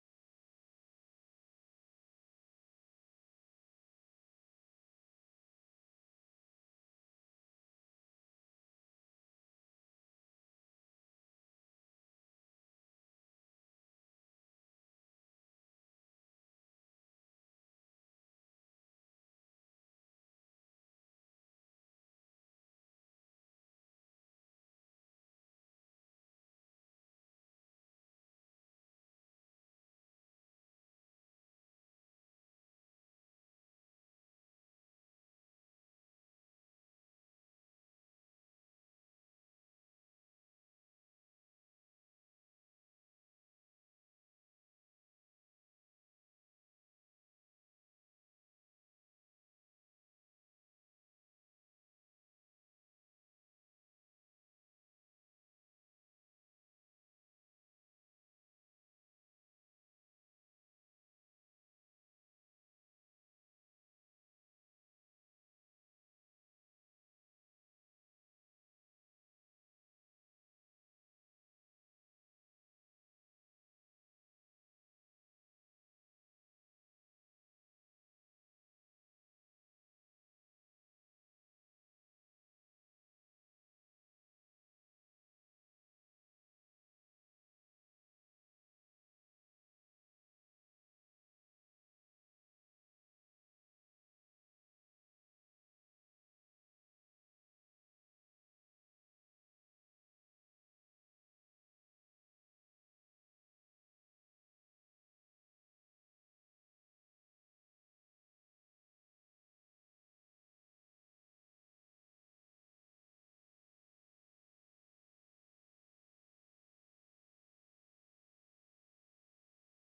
with the lead guitars removed